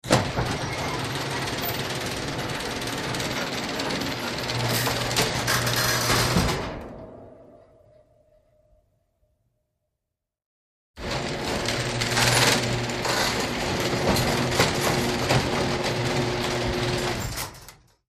Garage Door 1; Electric Garage Door Opener Opening And Closing Garage Door. Medium Perspective.